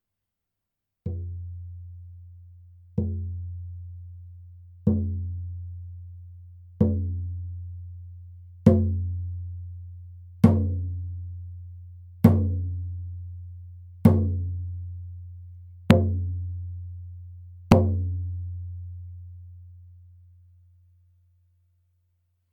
FRAME DRUM Native American (Indian) style
素材：牛革・天然木
パキスタン製フレームドラム 音